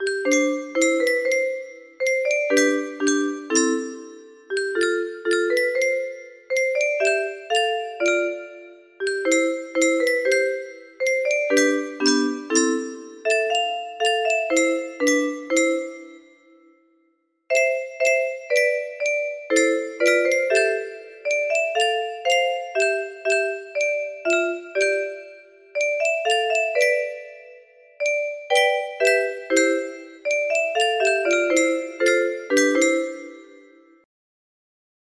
1111 music box melody